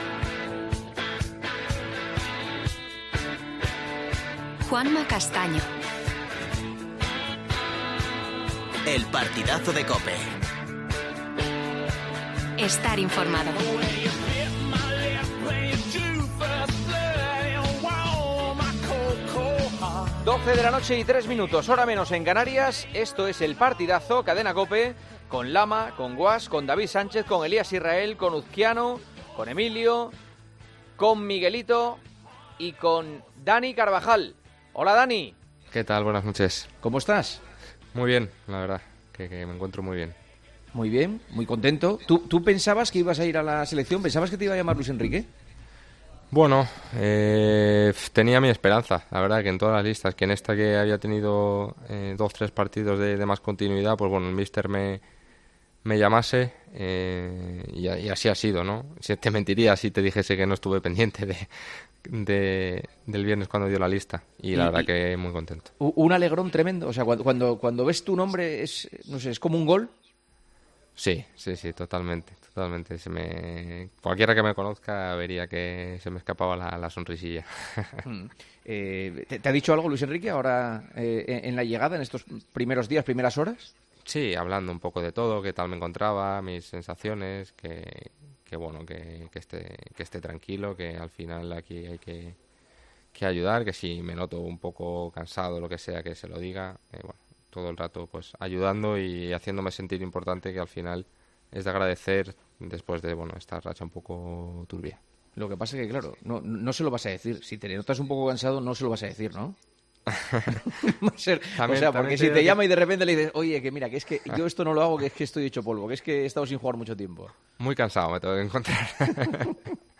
Juanma Castaño entrevistó en El Partidazo de COPE a Dani Carvajal, defensa de la Selección Española y del Real Madrid, para hablar de su vuelta tras un...